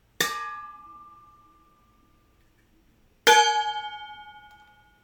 This is a thermos.